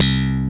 Bass (19).wav